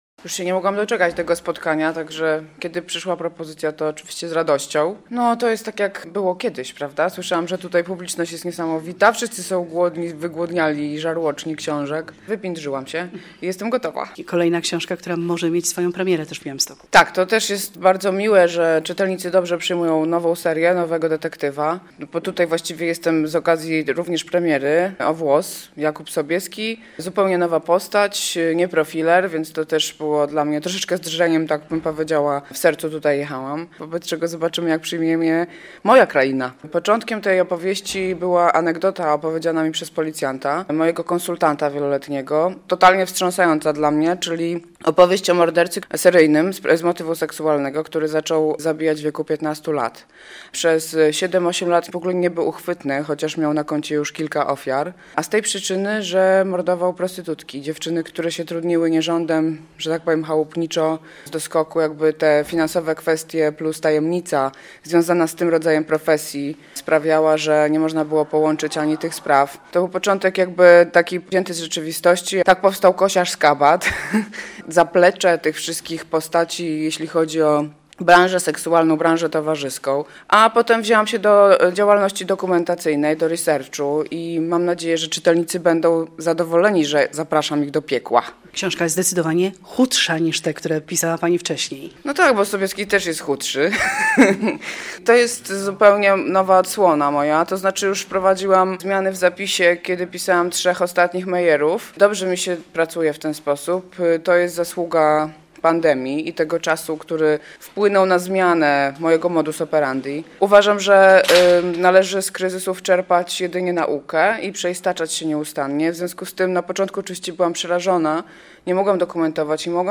9. Targi Książki i festiwal "Na pograniczu kultur" w Białymstoku
Z Katarzyną Bondą rozmawia